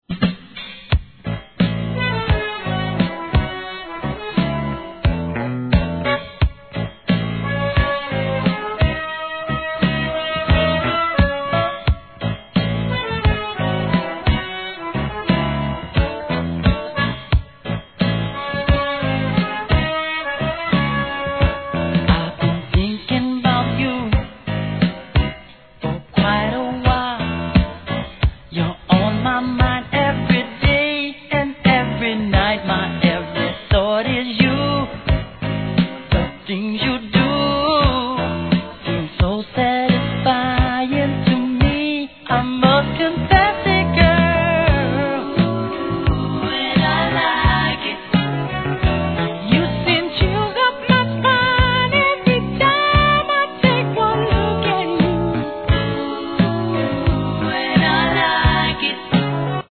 ¥ 1,650 税込 関連カテゴリ SOUL/FUNK/etc...